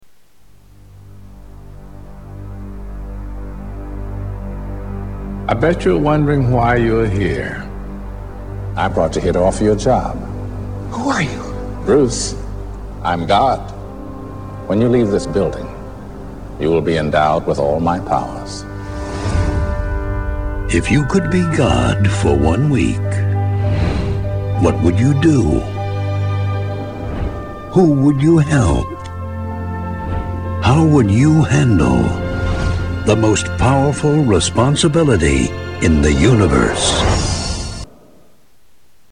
Play audio of trailer to "Bruce Almighty" .